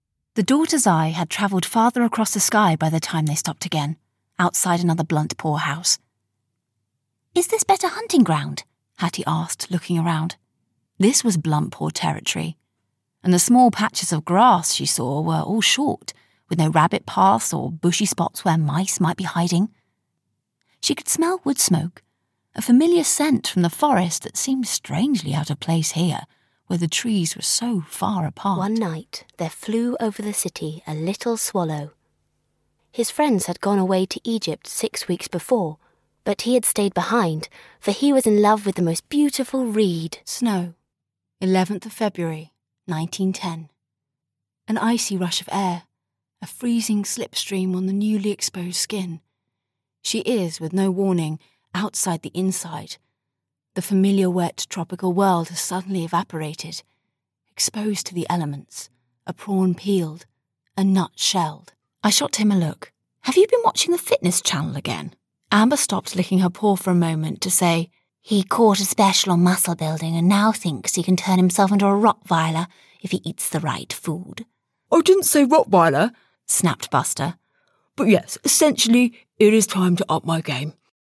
Multi-award winning British voice artist and actor
Narrative - Audiobook, Demo Reel
English - British RP